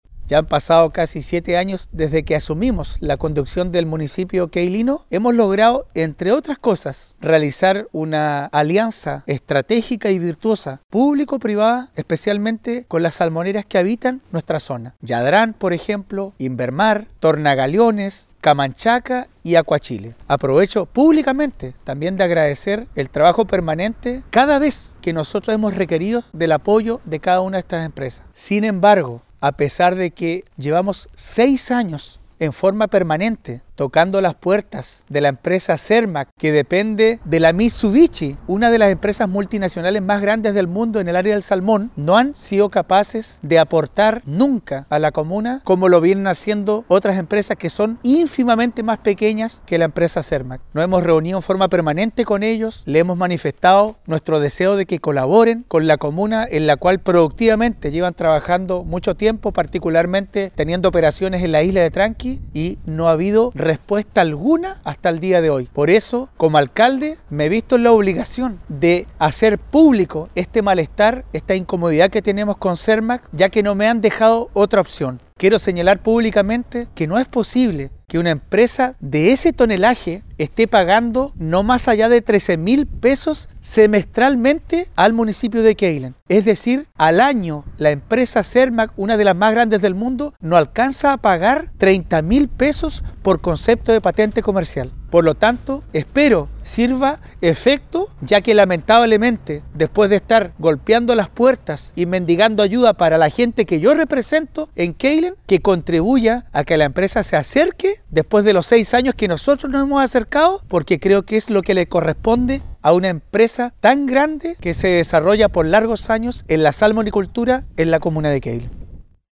Escuchemos lo declarado por el alcalde Marcos Vargas al respecto.